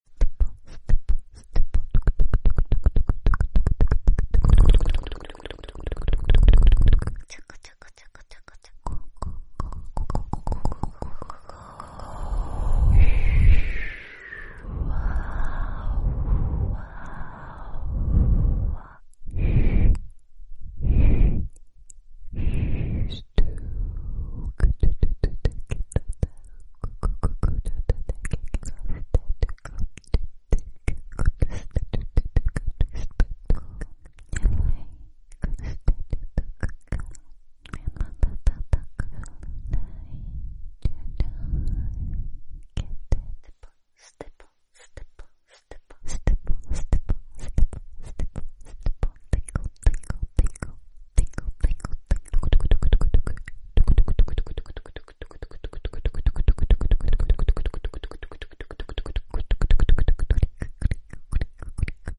ASMR 7 Types of Whispers sound effects free download
ASMR 7 Types of Whispers for Beginners